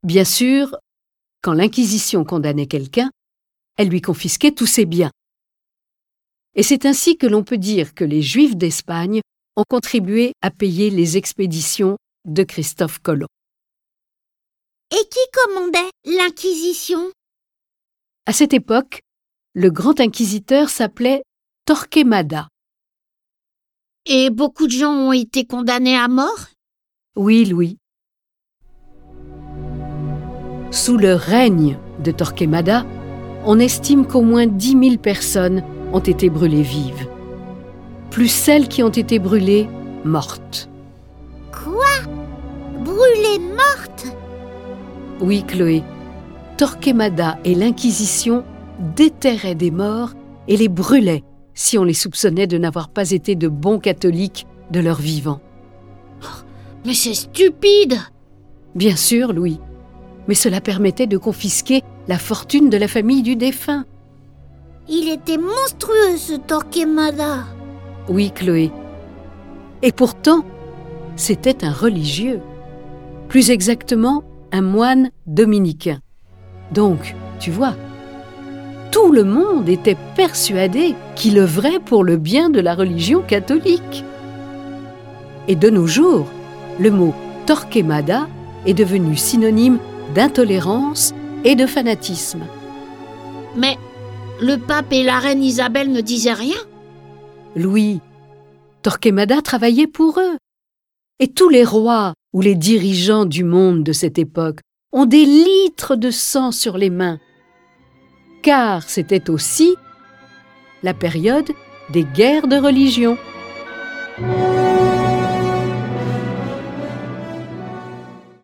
Diffusion distribution ebook et livre audio - Catalogue livres numériques
Le récit de la vie d'Isabelle la Catholique est animé par 7 voix et accompagné de 30 morceaux de musique classique.